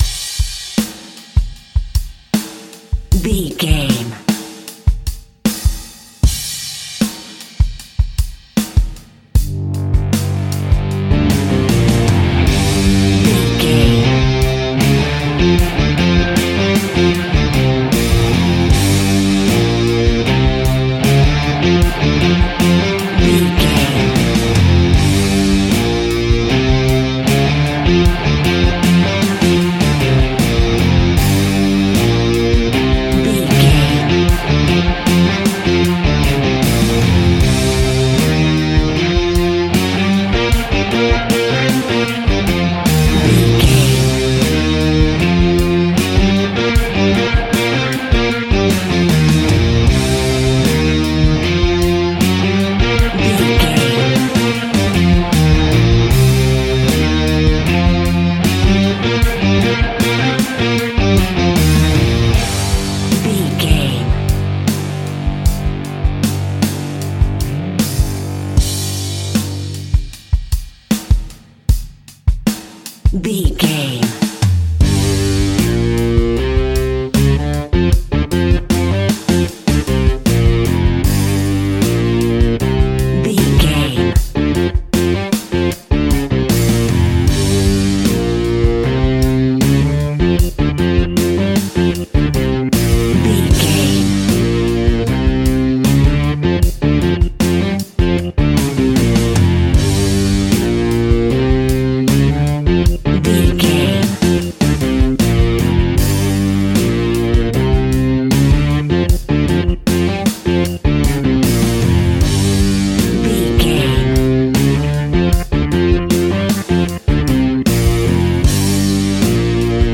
Rock Music Theme.
Aeolian/Minor
heavy metal
heavy rock
distortion
Instrumental rock
drums
electric guitar
bass guitar
hammond organ
Distorted Guitar
heavy guitars